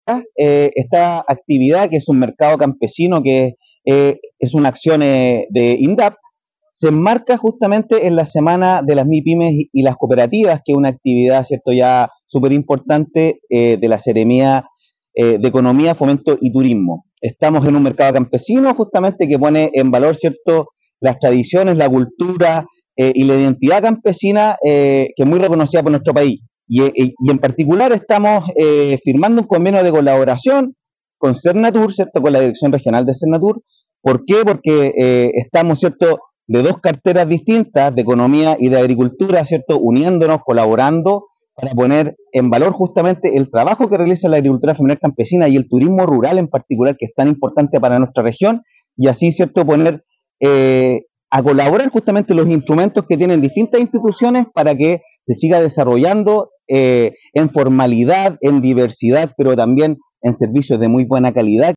La actividad se realizó junto a productoras y cultoras de la región que dieron vida a una nueva versión del tradicional “Mercado Campesino” de Indap, en instalaciones del Parque Costanera de Puerto Montt; en el marco de la Semana de las Mipymes y Cooperativas 2024 que desarrolló la Seremía de Economía y sus servicios dependientes.
Tras oficializar el convenio, el representante de Indap a nivel regional Francisco Parada, destacó esta estrategia de trabajo biministerial que apunta a seguir potenciando esta importante actividad productiva de la región.